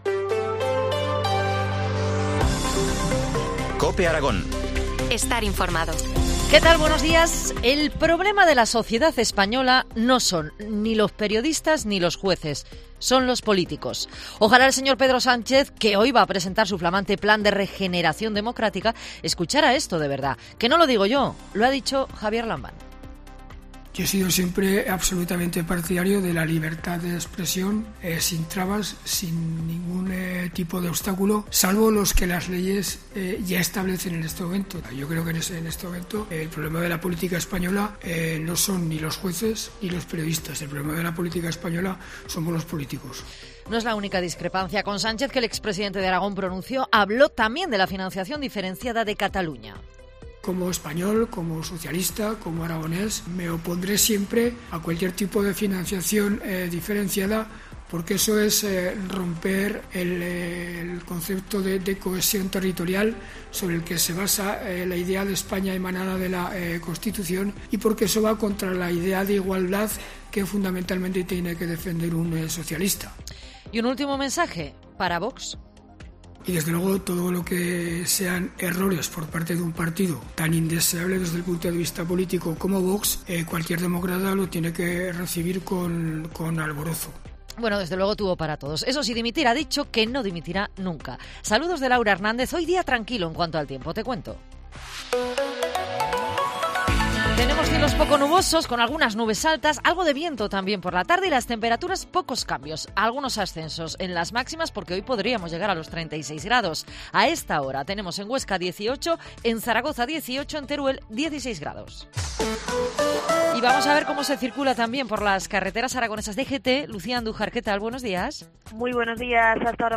Titulares del día en COPE Aragón